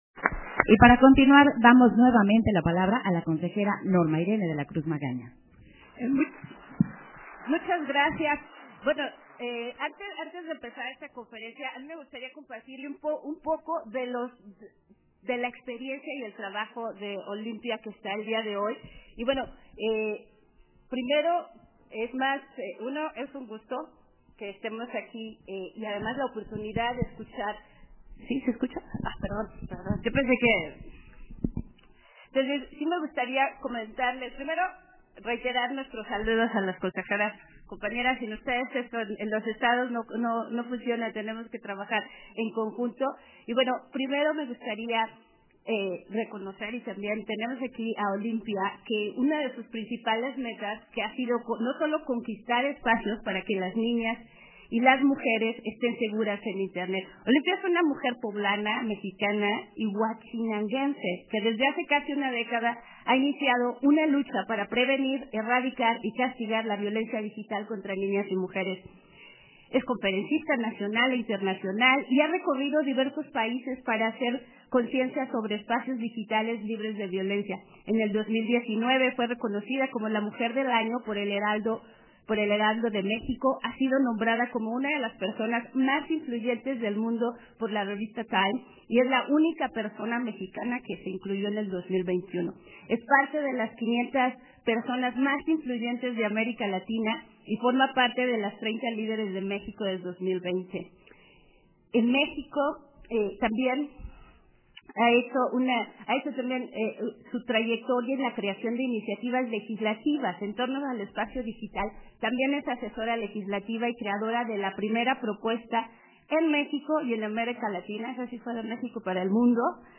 251122_AUDIO_CONFERENCIA-MAGISTRAL-VIOLENCIA-DIGITAL-Y-MEDIÁTICA - Central Electoral